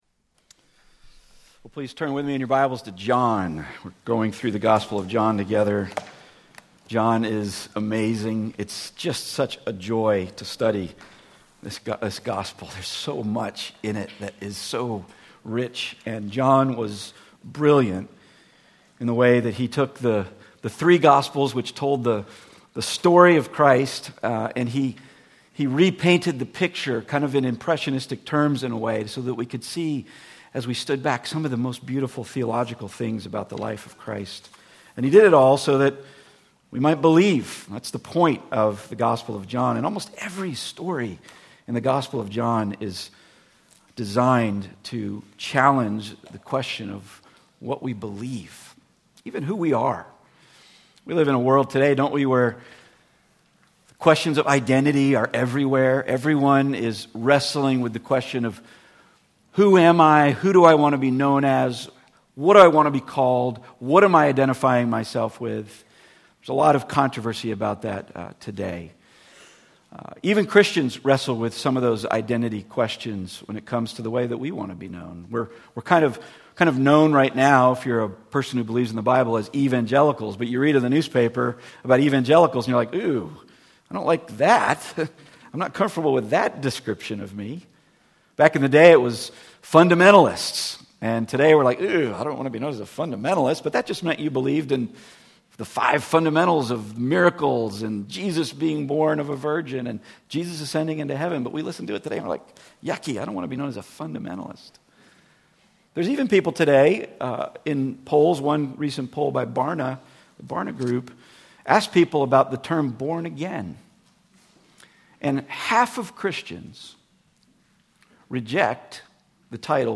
The Book of John Passage: John 3:1-15 Service Type: Weekly Sunday